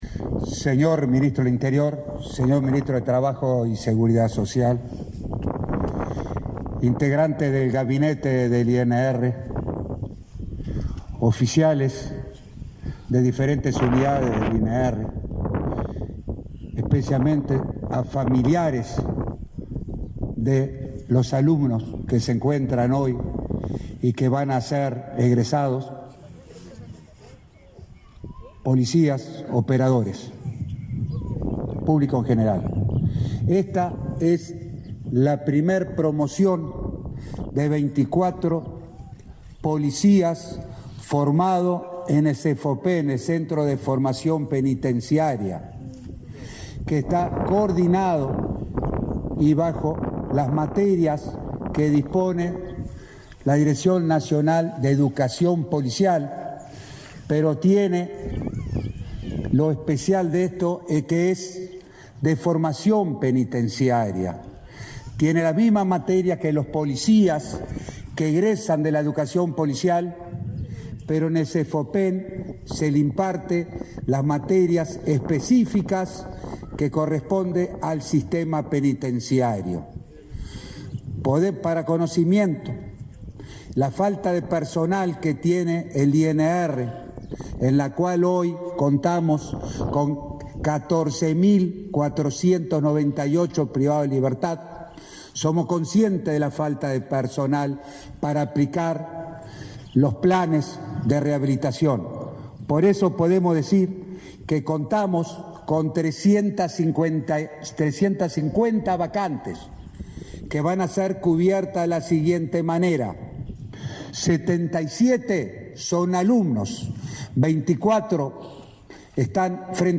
Conferencia de prensa por egreso de los primeros policías capacitados en el Centro de Formación Penitenciaria
El ministro del Interior, Luis Alberto Heber, participó, este 21 de julio, en la ceremonia de egreso de 24 agentes de policía penitenciarios.
En el evento, también hizo uso de la palabra el director del Instituto Nacional de Rehabilitación, Luis Mendoza.